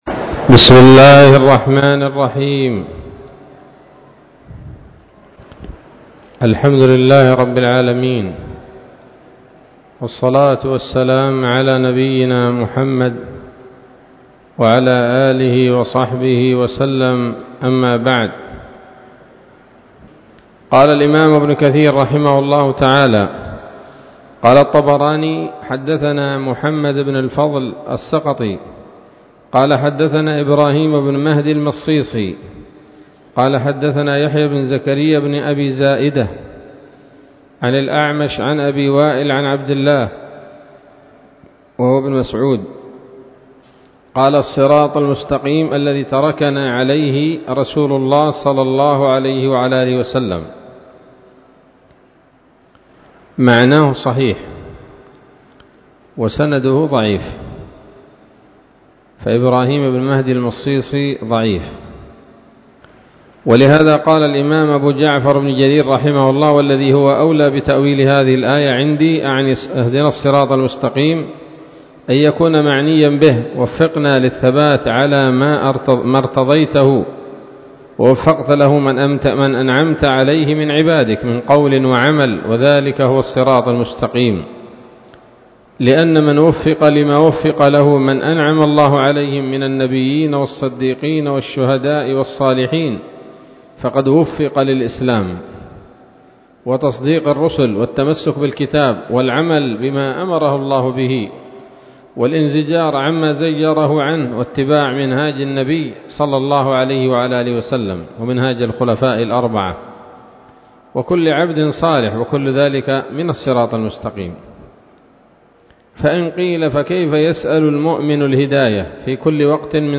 الدرس التاسع عشر من سورة الفاتحة من تفسير ابن كثير رحمه الله تعالى